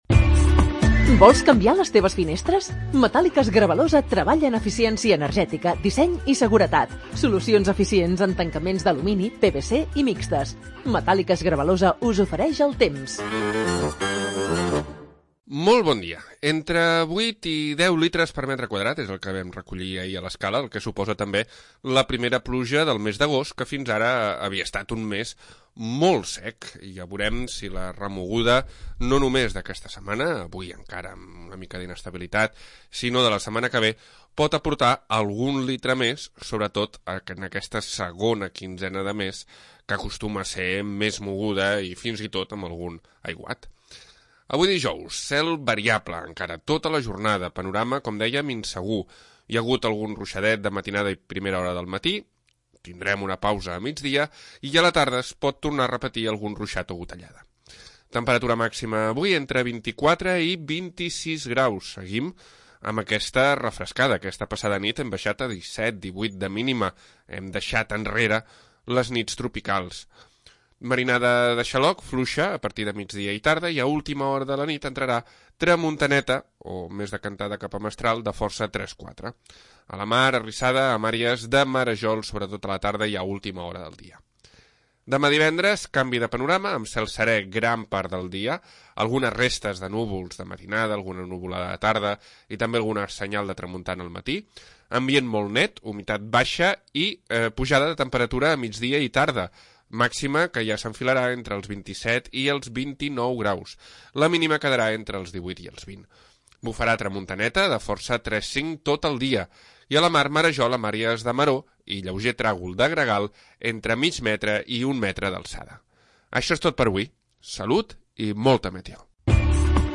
Previsió meteorològica 21 d'agost de 2025